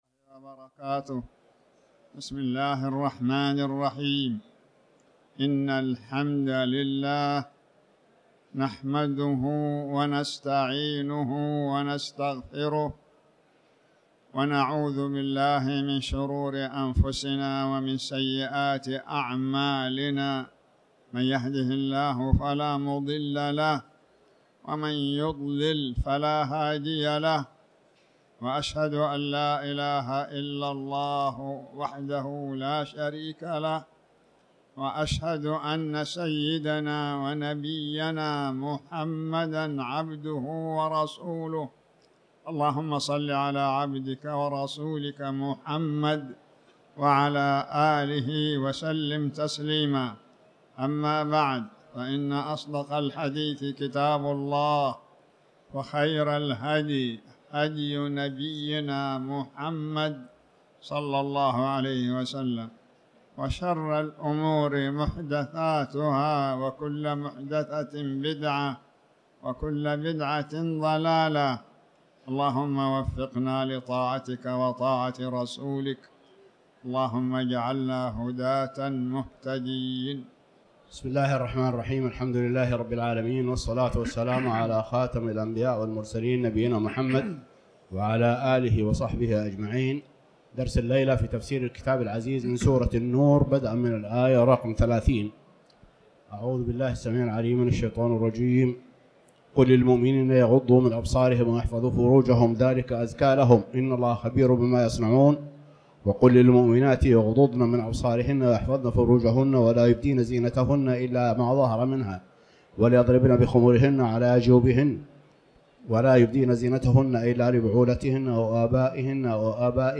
تاريخ النشر ١٠ ربيع الثاني ١٤٤٠ هـ المكان: المسجد الحرام الشيخ